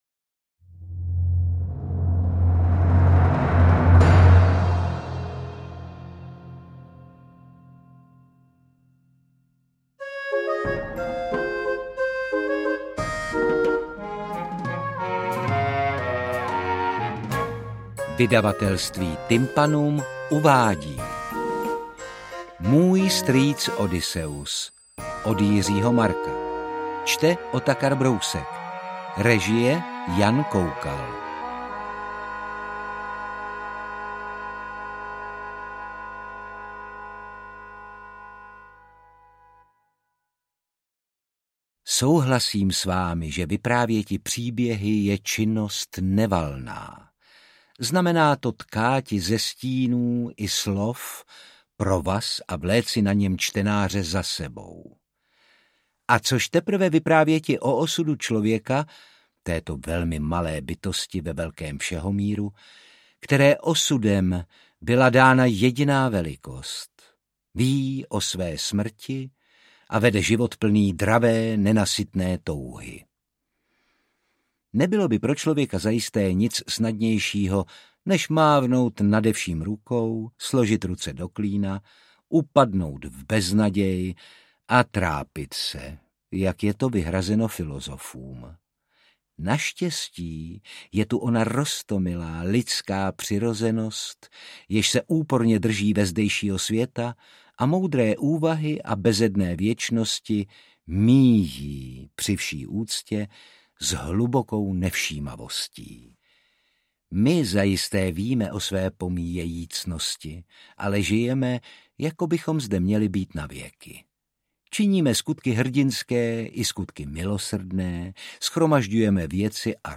Interpret:  Otakar Brousek
AudioKniha ke stažení, 31 x mp3, délka 13 hod. 2 min., velikost 716,2 MB, česky